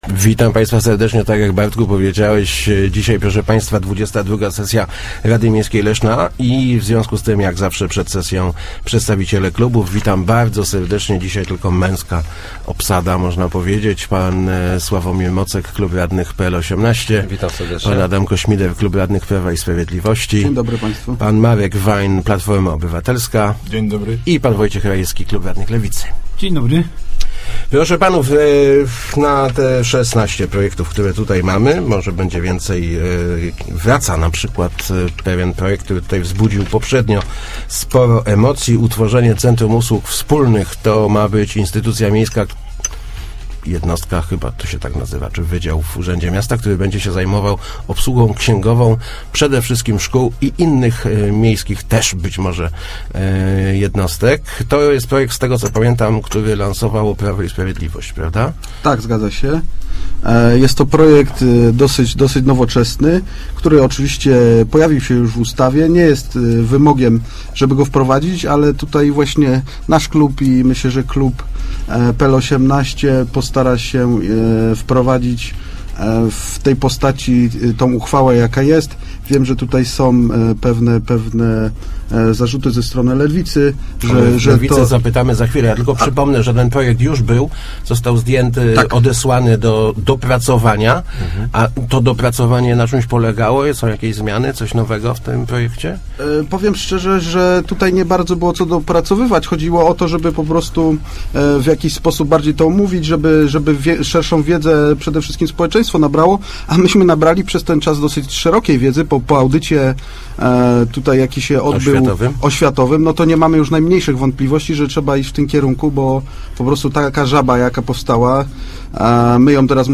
Na obrady Rady Miejskiej wraca pomys� utworzenia Centrum Us�ug Wspólnych, czyli jednostki obs�uguj�cej ksi�gowo�� szkó� i przedszkoli. - B�dzie to lekarstwo na gangren�, jaka toczy nasz� o�wiat� - zapewni� w Rozmowach Elki radny Adam Ko�mider z PiS.